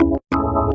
键盘 " 风琴01
描述：记录自DB33Protools Organ。 44khz 16位立体声，无波块。
Tag: 键盘 器官 DB33